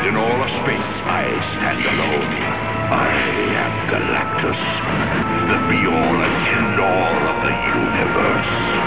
From the Fantastic Four animated series.